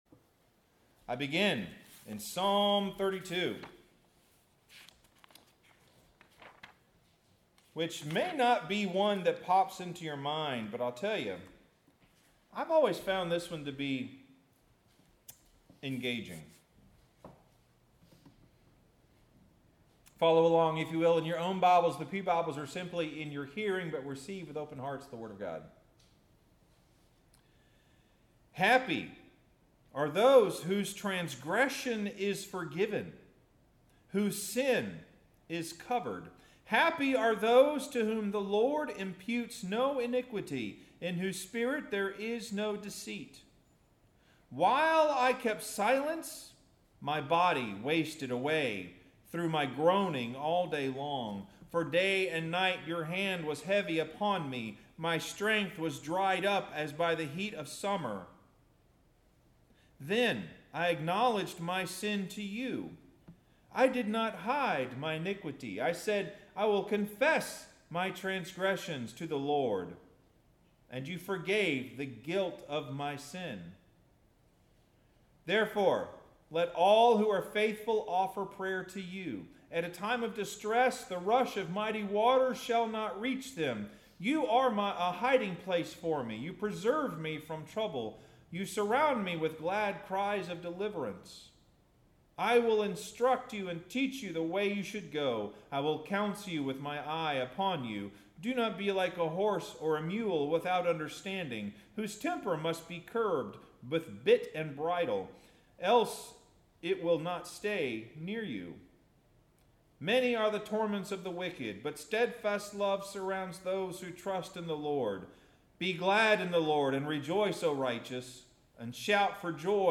Sermon – We Do Have Something to Say